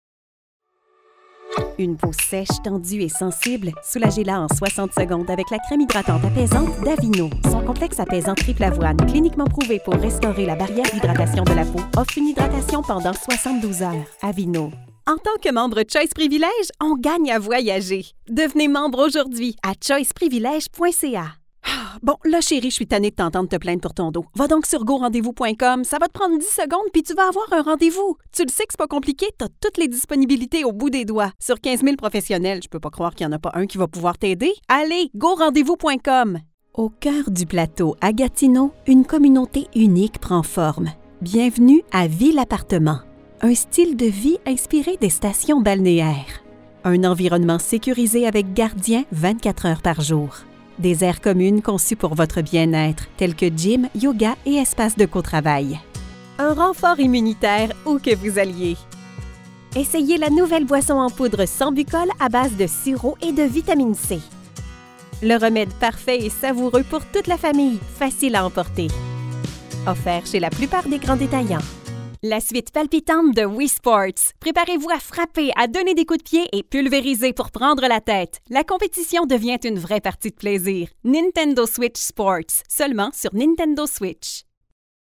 Naturelle, Polyvalente, Amicale, Accessible, Fiable
Commercial